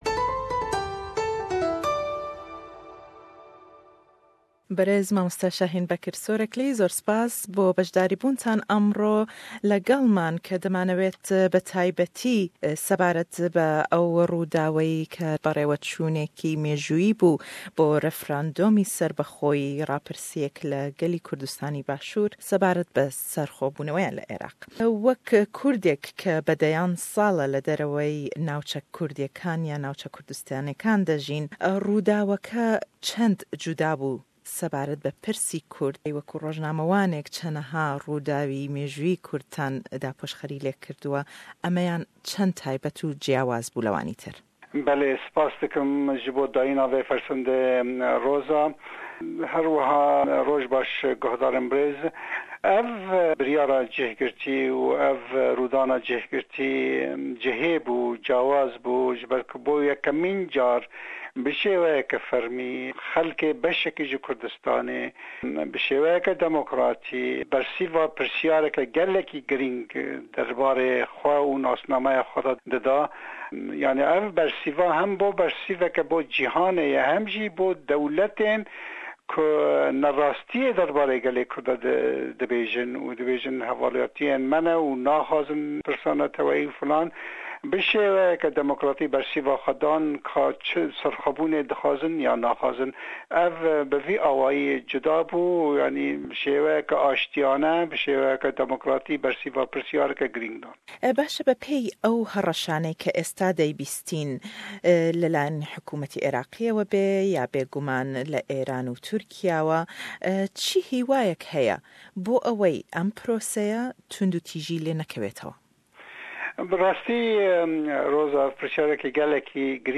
Hevpeyvîn le gell rojnamevan